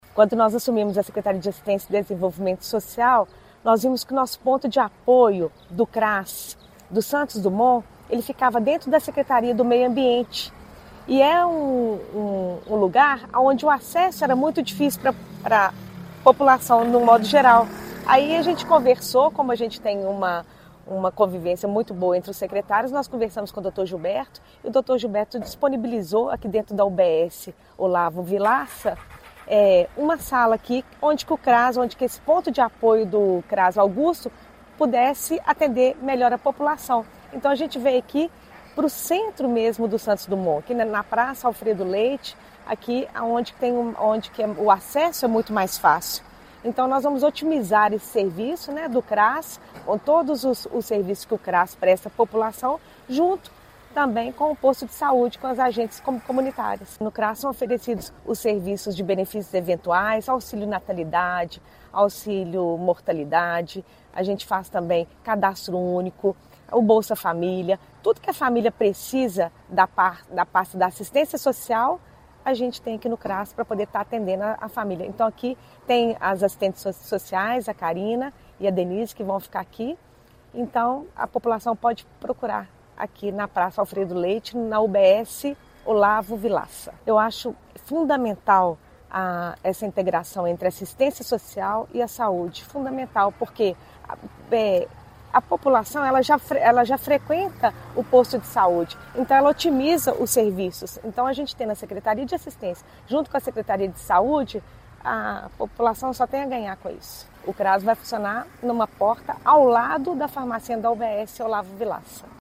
A secretária municipal de Assistência e Desenvolvimento Social, Cláudia Assunção Faria, destacou que a mudança foi pensada para facilitar a vida da população.